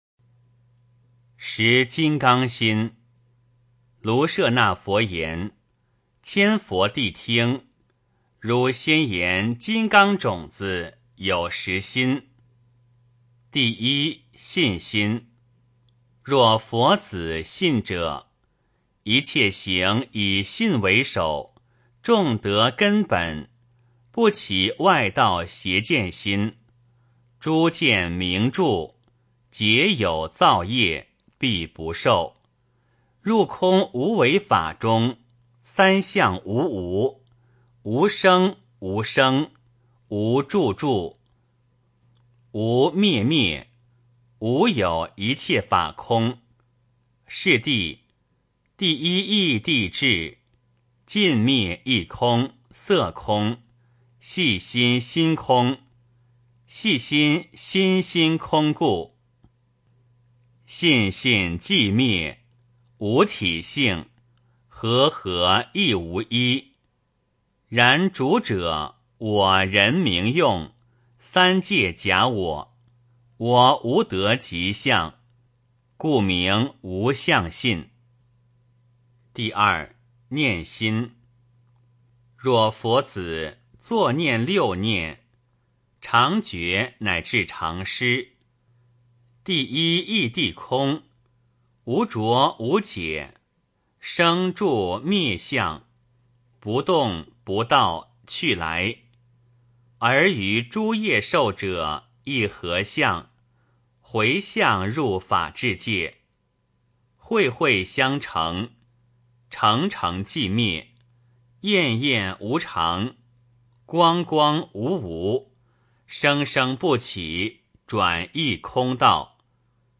梵网经-十金刚心 - 诵经 - 云佛论坛